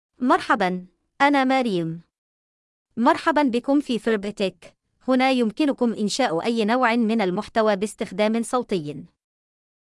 Maryam — Female Arabic (Yemen) AI Voice | TTS, Voice Cloning & Video | Verbatik AI
FemaleArabic (Yemen)
Voice sample
Listen to Maryam's female Arabic voice.
Female
Maryam delivers clear pronunciation with authentic Yemen Arabic intonation, making your content sound professionally produced.